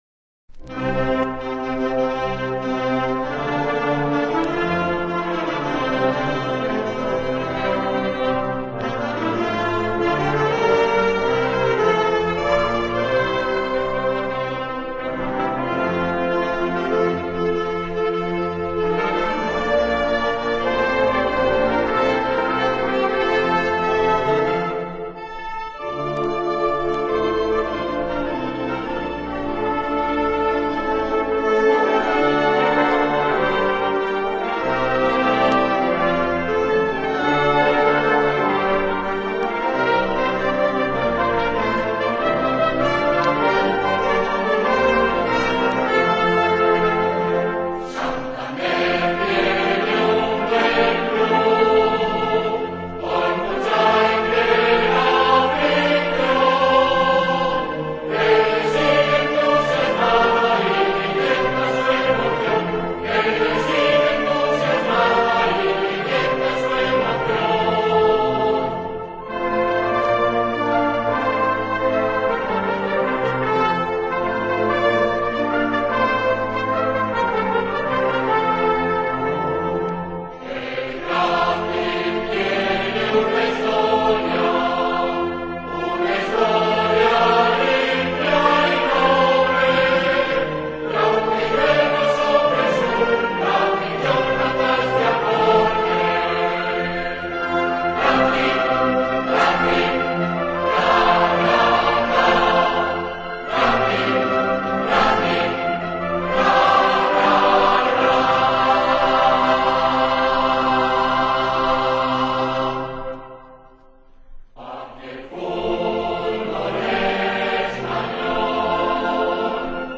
Himnos